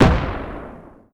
EXPLOSION_Arcade_09_mono.wav